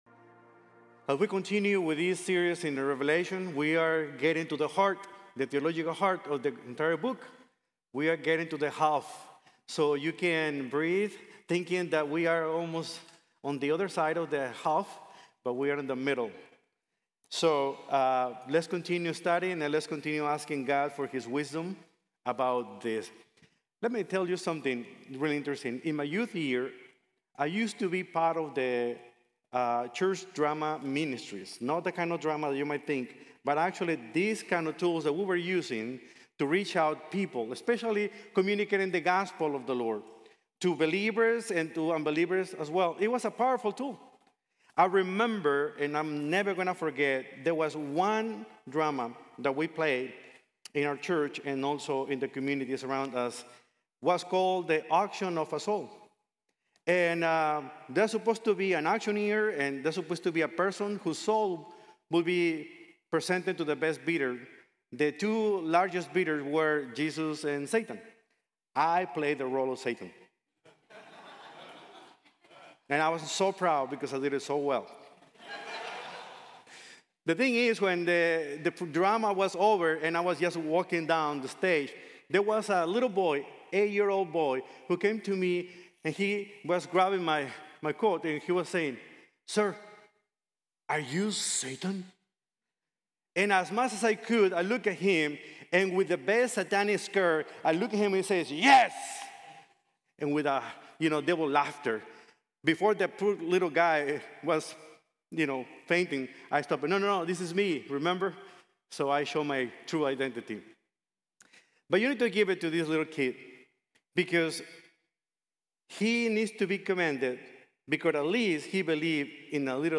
The Cosmic Conflict | Sermon | Grace Bible Church